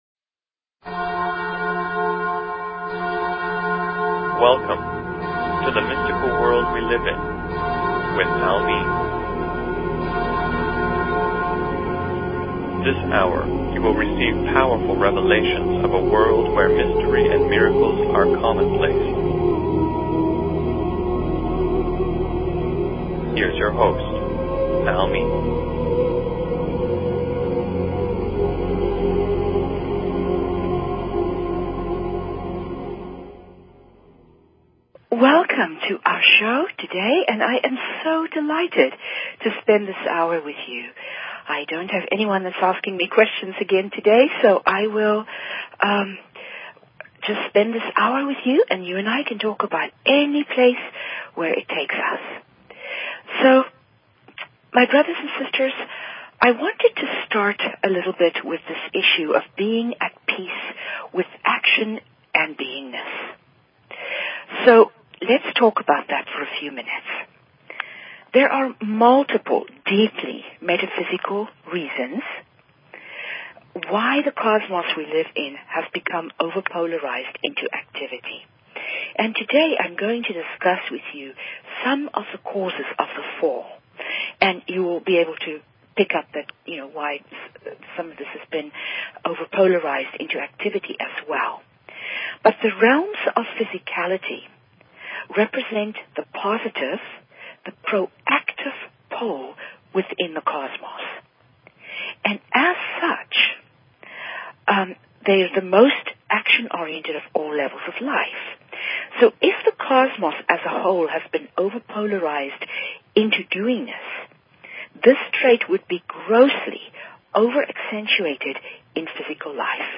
Talk Show Episode, Audio Podcast, The_Mystical_World_we_live_in and Courtesy of BBS Radio on , show guests , about , categorized as